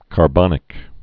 (kär-bŏnĭk)